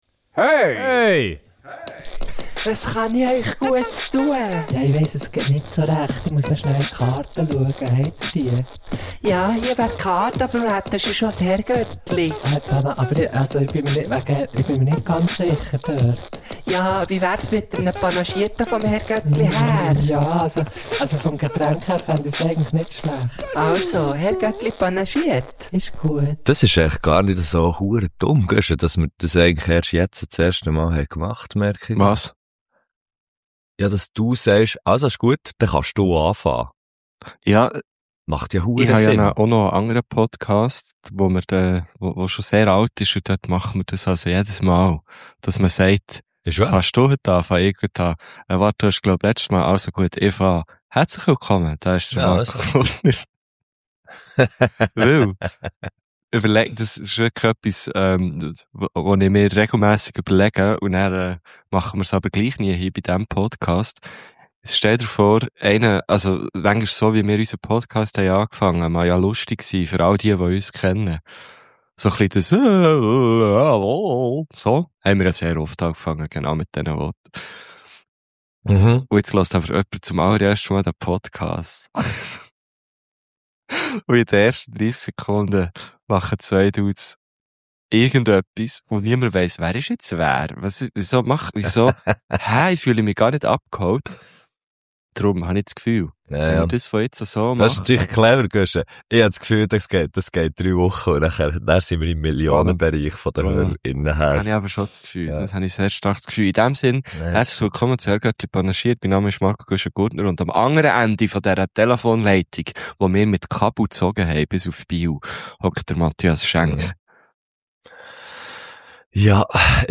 Es ist weder eine Göttlifolge, noch haben wir eine Gästin oder einen Gast am Start.
Die Stimmung eher so bitzli angespannt.
Nach der Kaltfront in den ersten paar Minuten heitert es dann aber zügig auf und der Rest der Folge ist ein einziges wohltuendes Hochdruckgebiet, das einem das Herz erwärmt.